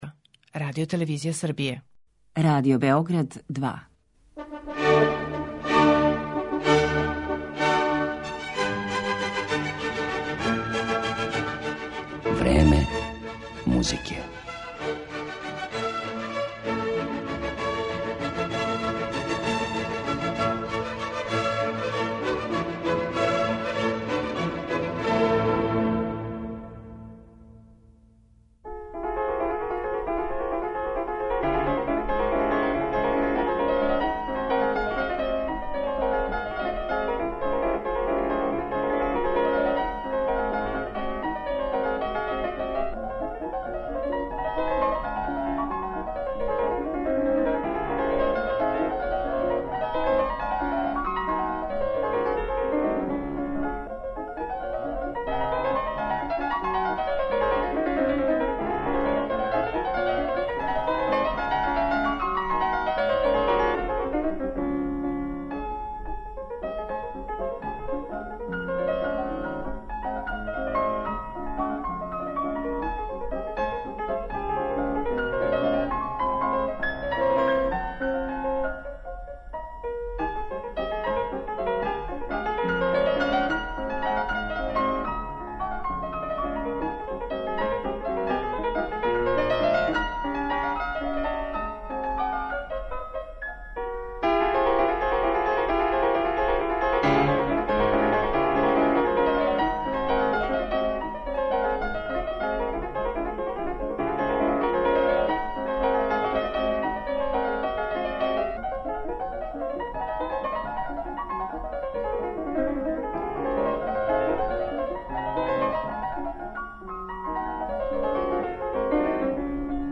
У данашњем Времену музике , овог свестраног ствараоца представићемо кроз његове интерпретације дела Фредерика Шопена, Лудвига ван Бетовена, Франца Листа и Јохана Себастијана Баха.